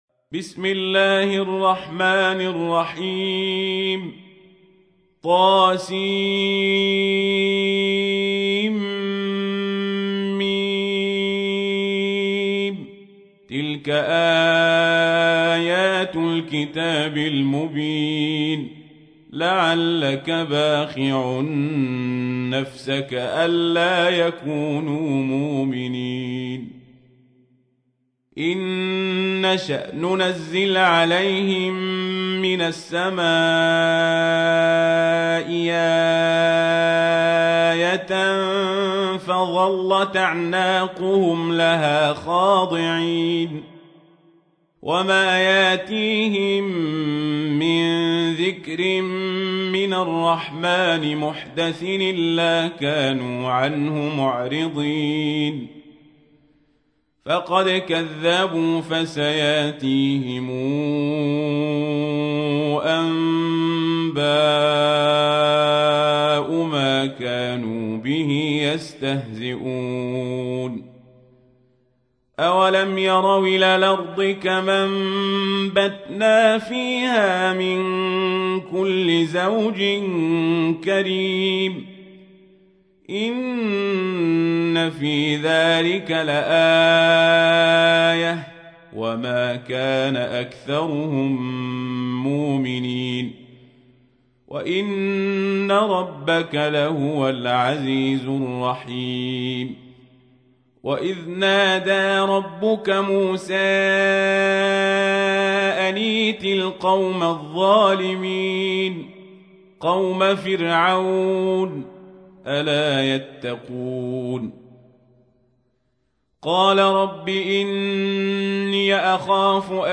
تحميل : 26. سورة الشعراء / القارئ القزابري / القرآن الكريم / موقع يا حسين